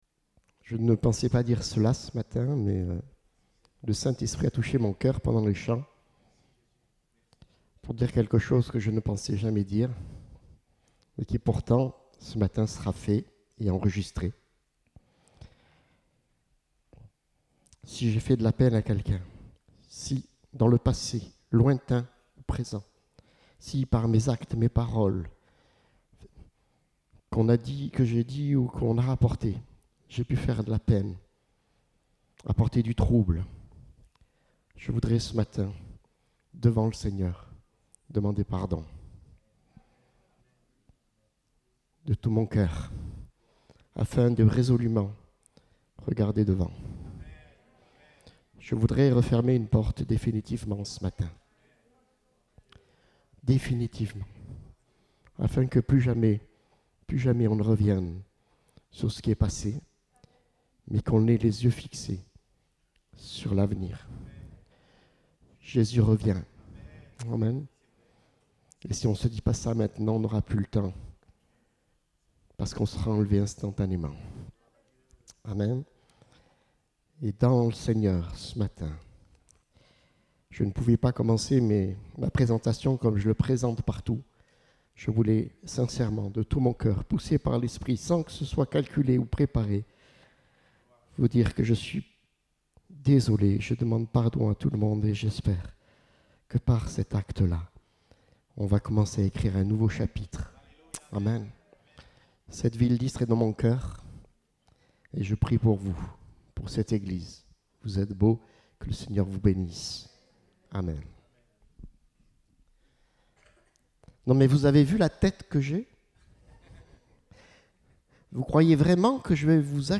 Date : 28 novembre 2021 (Culte Dominical)